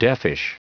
Prononciation du mot deafish en anglais (fichier audio)
Prononciation du mot : deafish